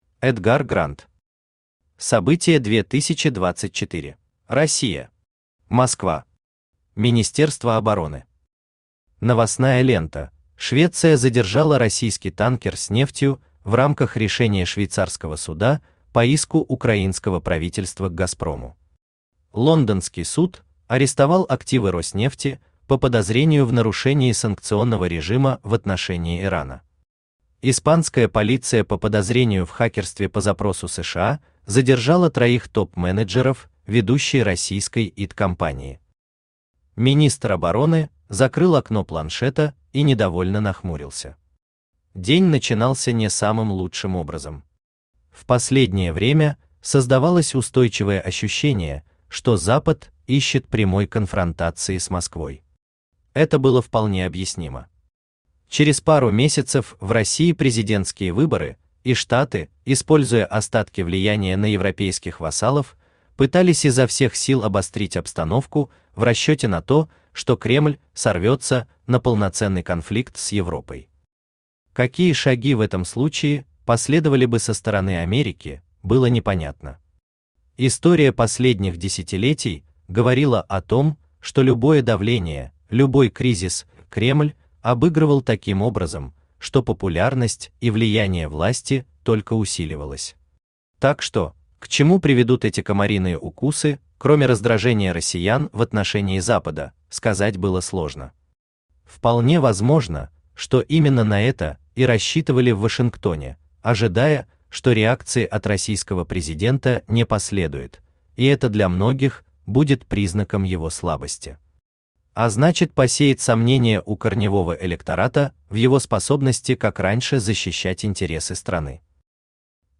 Аудиокнига Событие 2024 | Библиотека аудиокниг
Aудиокнига Событие 2024 Автор Эдгар Грант Читает аудиокнигу Авточтец ЛитРес.